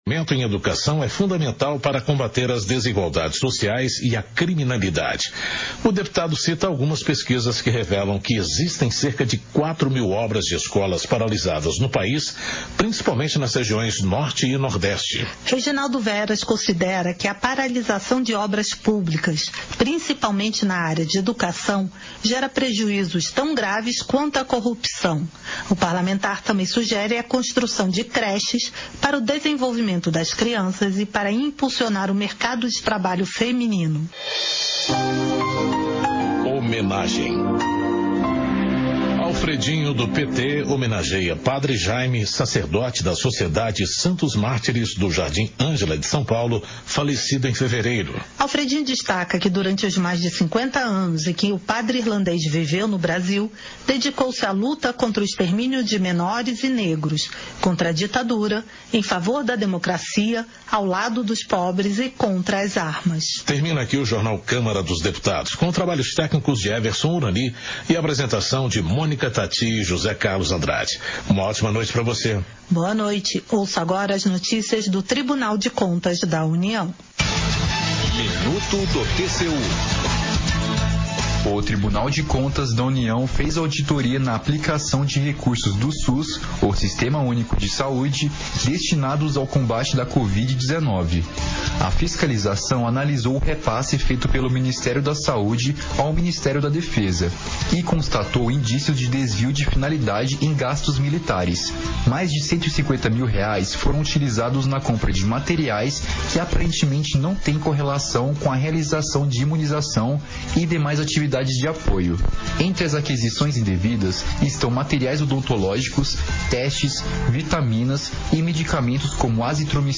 Sessão Ordinária 09/2023